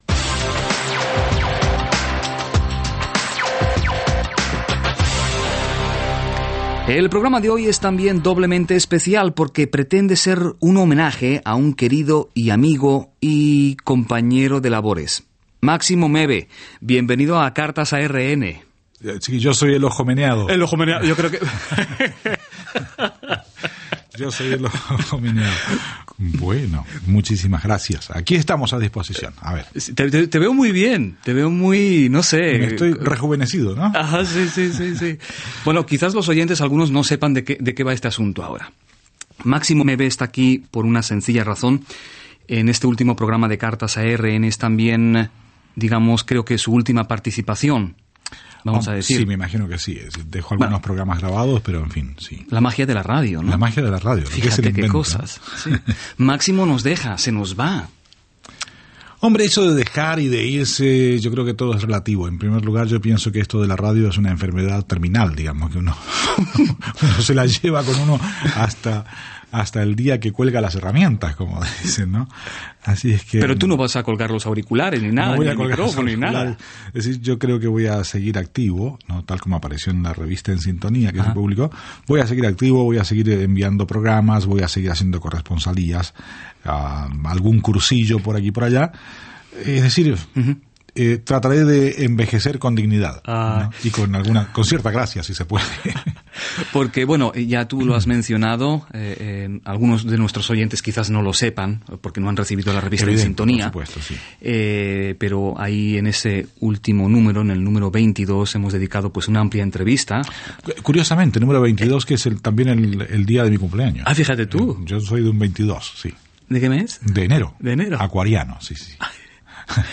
entrevista en RN
Archivo digital obtenido en el antiguo sitio de Radio Nederland.